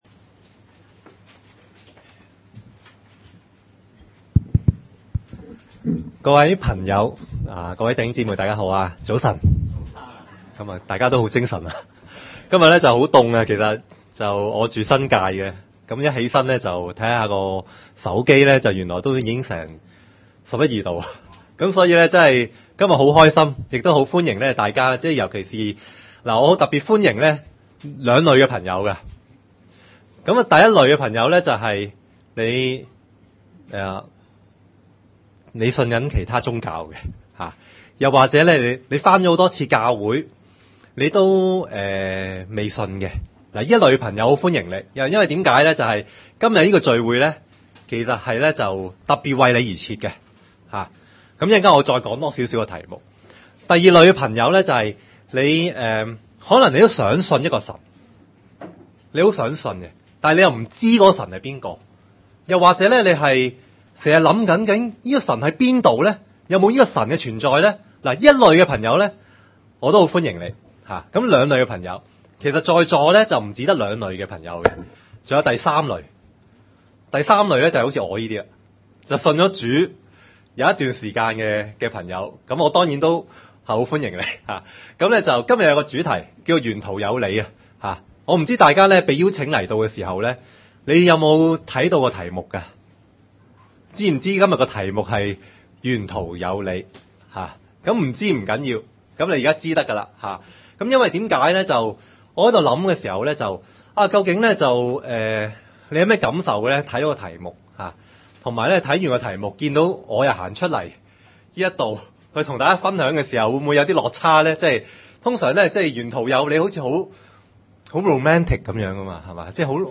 福音聚會：沿途有祢